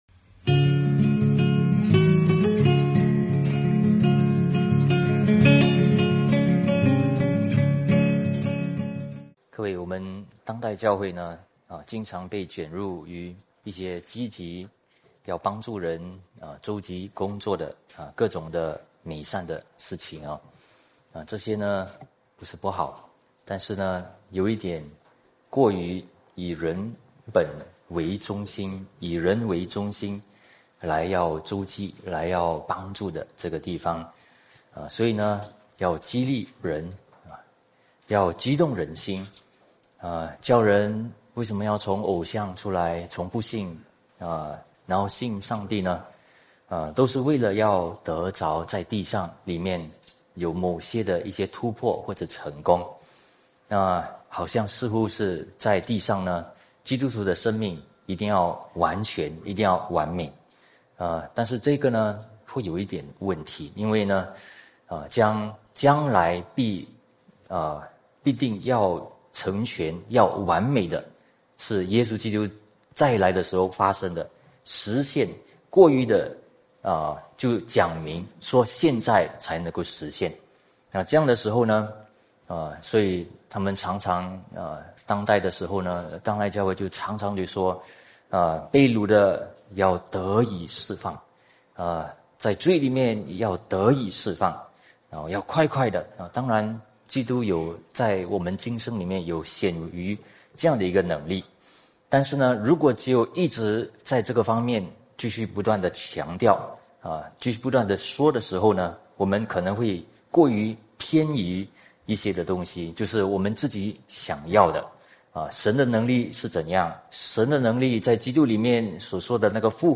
主日信息